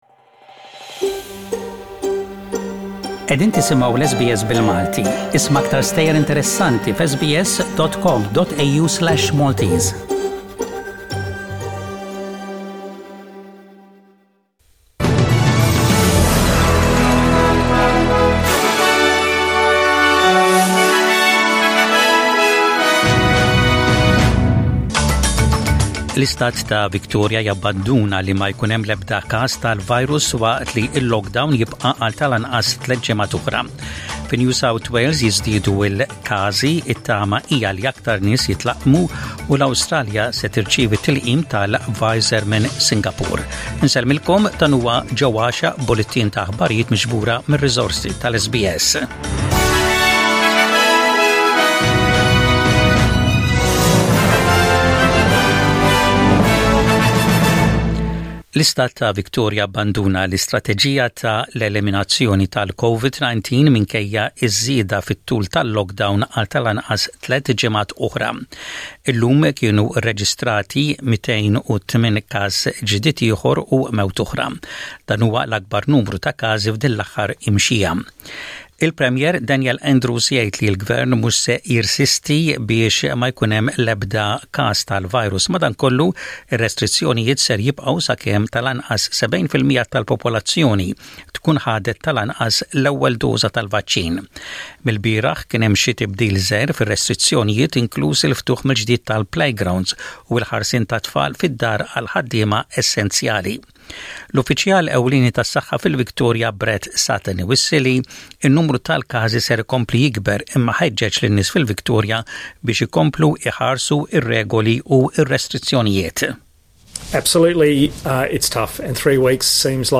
SBS Radio | Maltese News: 03/09/21